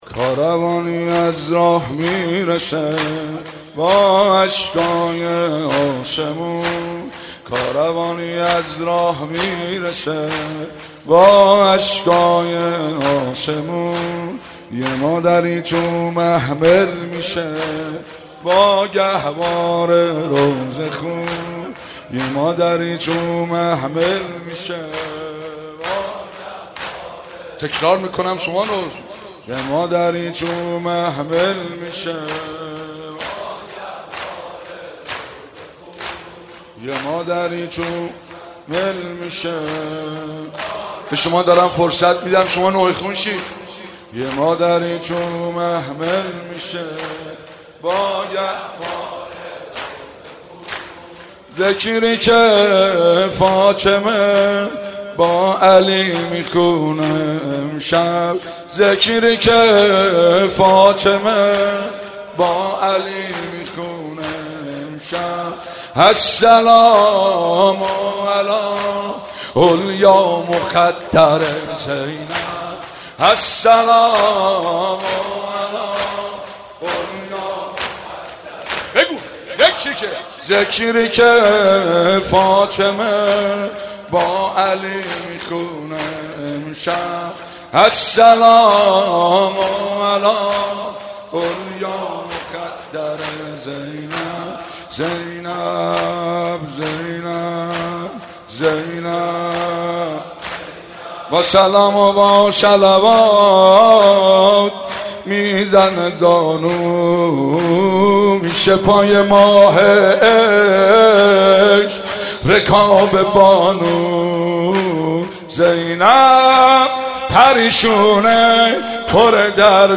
مداحی
در شب دوم محرم